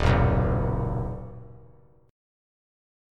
Ebm#5 chord